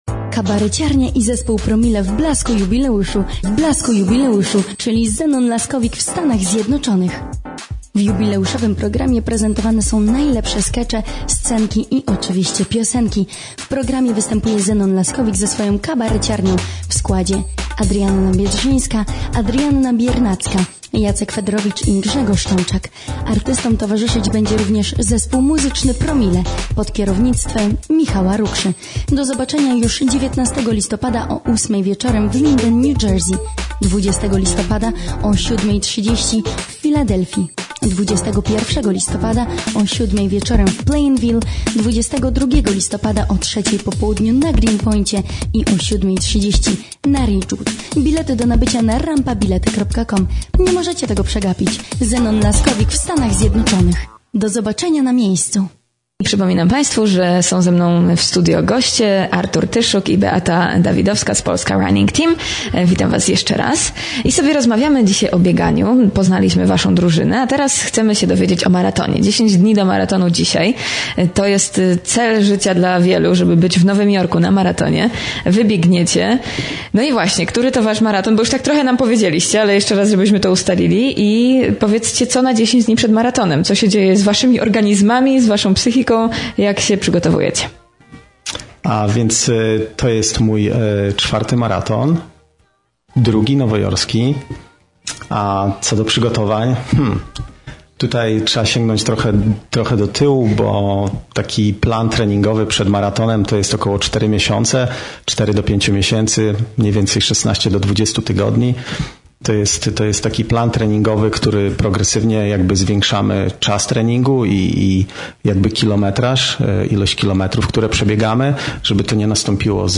Codziennie w porannym Espresso, gościć będziemy biegaczy, przedstawicieli drużyny, lub gości z Polski, którzy wezmą udział w maratonie.